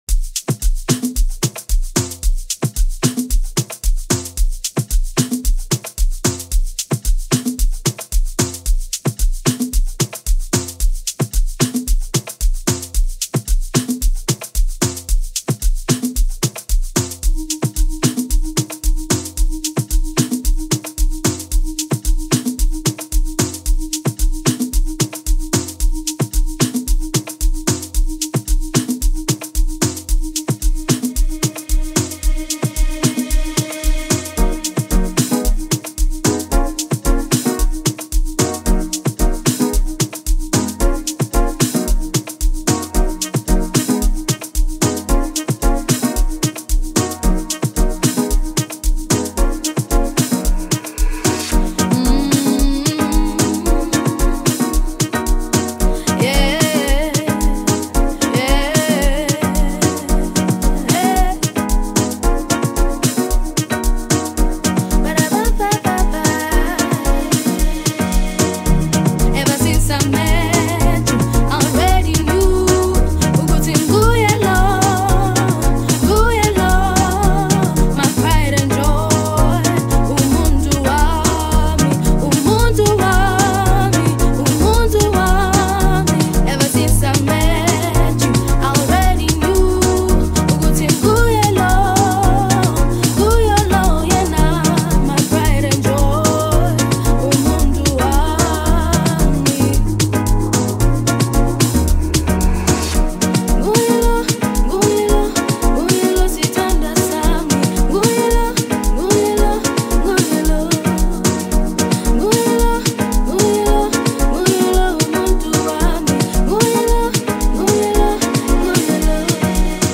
Amapiano songs
Today, she returns on vocal duties.
beautiful voice